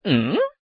あーん 効果音MP3